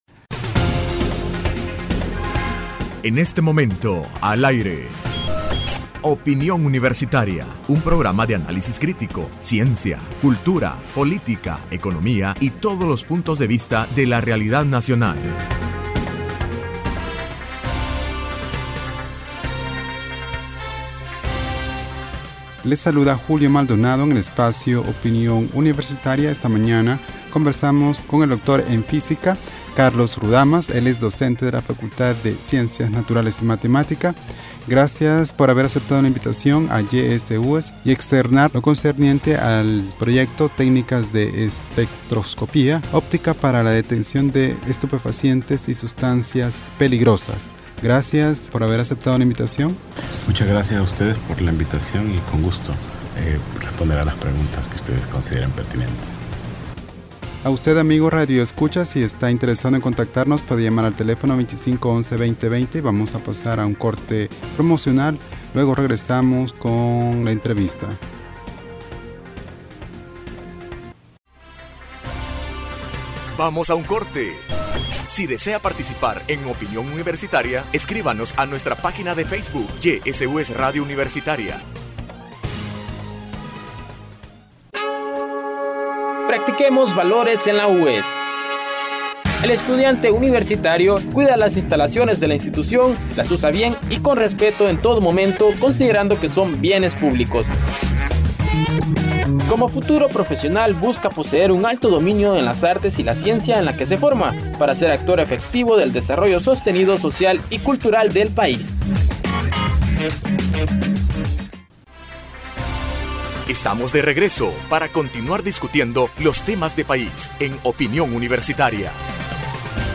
Entrevista Opinión Universitaria (1 septiembre 2015): Proyecto de cooperación UES y México sobre sustancias controladas.